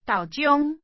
Hbl-豆醬-tāu-chiòⁿ.mp3 (tập tin âm thanh MP3, dài 0,8 s, 67 kbit/s tất cả)